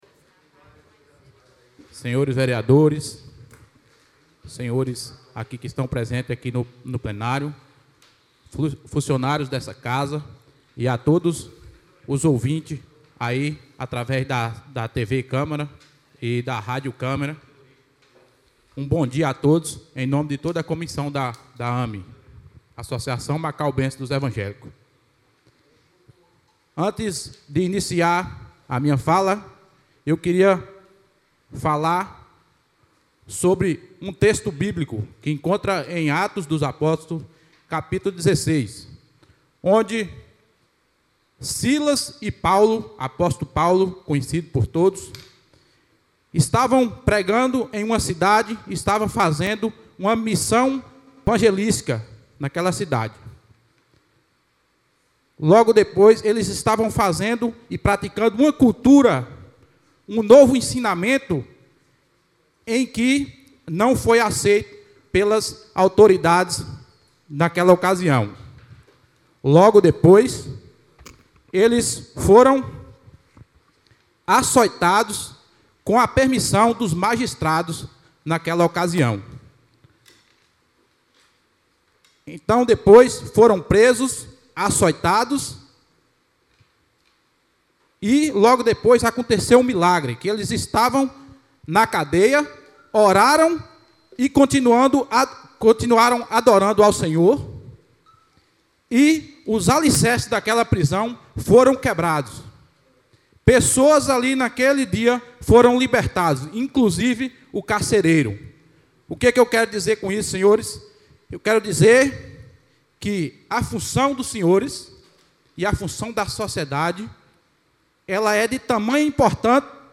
A 26ª Sessão Ordinária do Primeiro Período Legislativo da Legislatura 2017-2020 da Câmara Municipal de Macaúbas foi realizada na manhã desta terça-feira, dia 08 de maio de 2018.
O Presidente Anderson Gumes abriu a Sessão convidando todos os presentes para realizarem a oração de praxe, em seguida leu algumas correspondências.